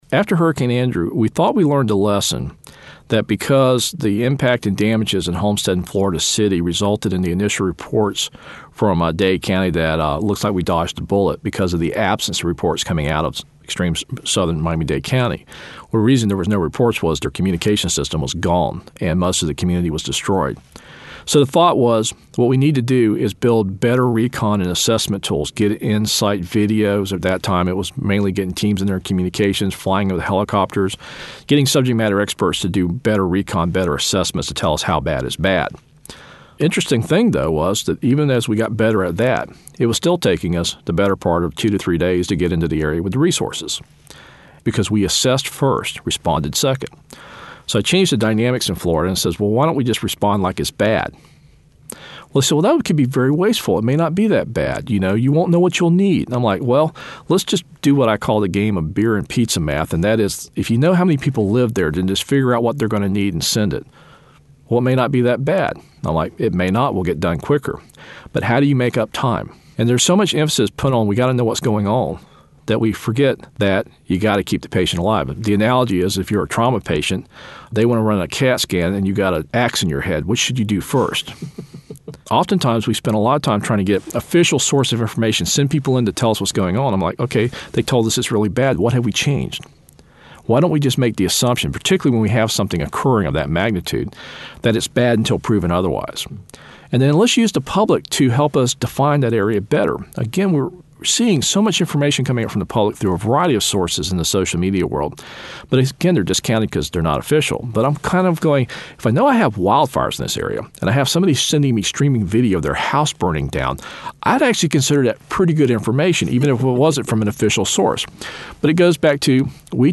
A Conversation with W. Craig Fugate, FEMA Administrator